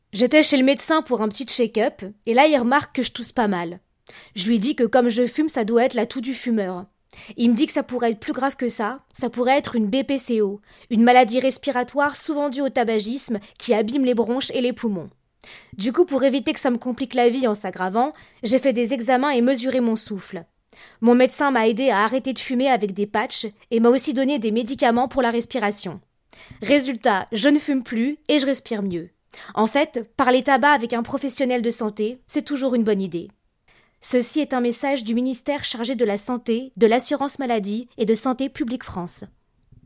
Maquette Ministère de la Santé (pub radio)